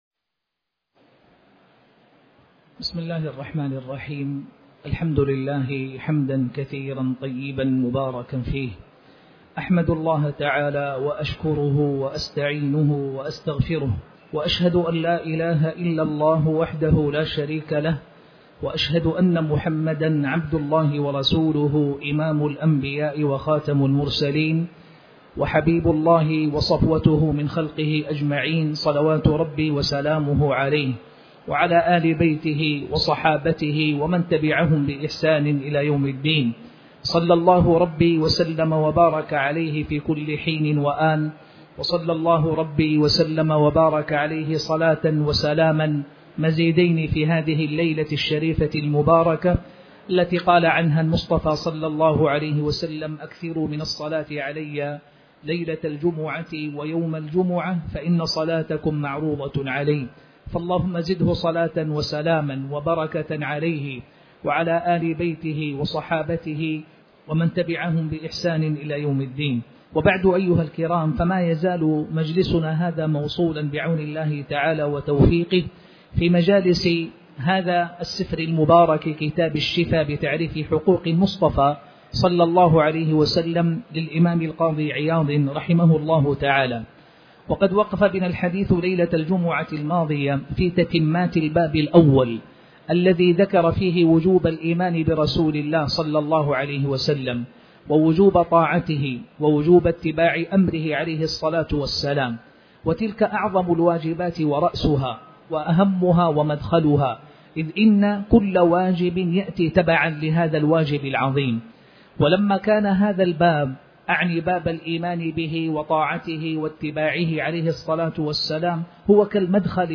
تاريخ النشر ١٦ صفر ١٤٤٠ هـ المكان: المسجد الحرام الشيخ